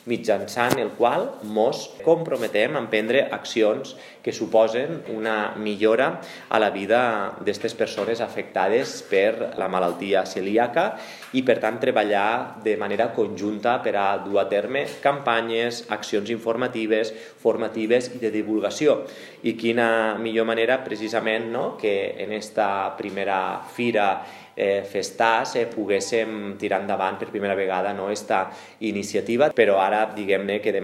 Pel que fa a l’espai lliure de gluten l’alcalde ha avançat que l’Ajuntament ha signat un conveni amb l’Associació de Celíacs de Catalunya mitjançant el qual s’emprendran accions que suposen una millora de vida de les persones afectades per la malaltia celíaca.